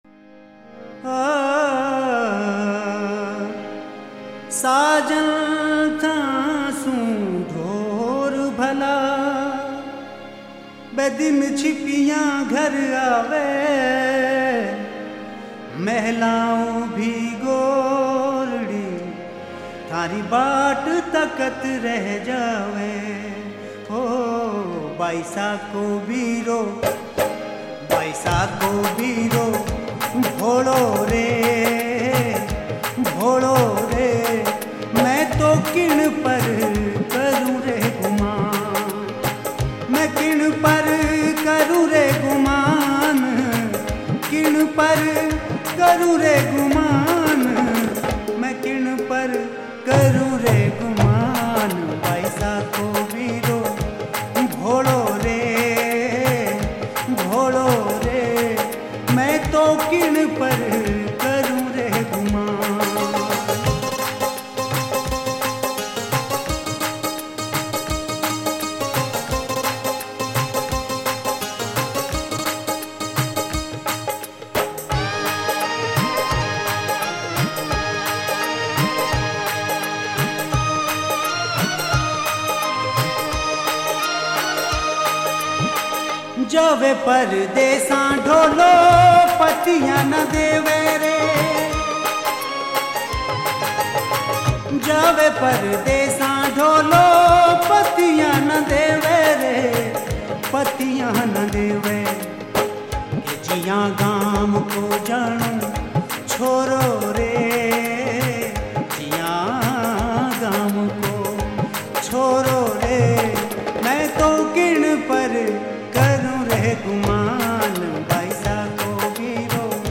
Rajasthani Folk Songs